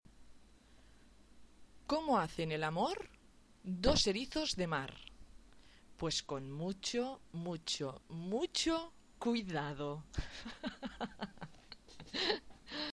descargar sonido mp3 ringtone gracioso